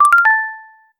Feelings: cosy, wood, positive. not too high. simple, soft. popping sound. bubbles. 0:15 tinder like notification sound when you get a match. celebration, playful, positive 0:01
tinder-like-notification--ijox5q3p.wav